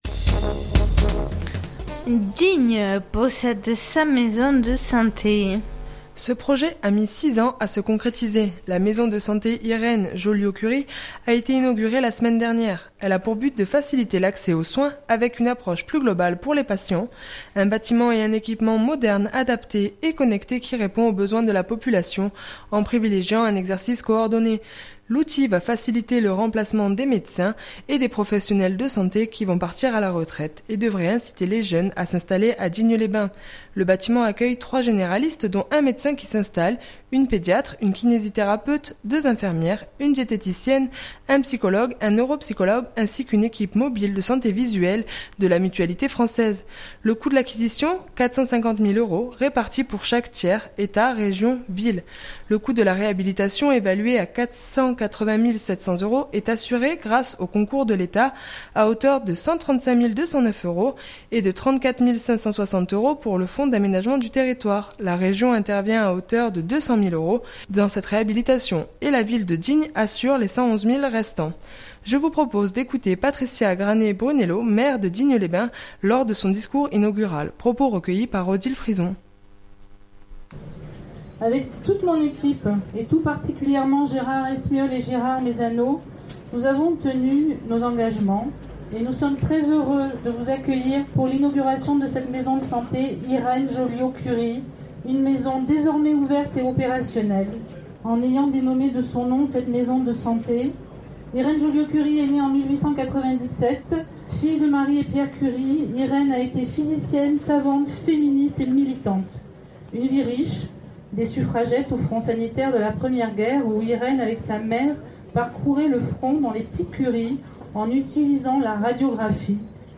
Je vous propose d’écouter Patricia Granet-Brunello maire de Digne-les-Bains lors de son discours inaugural.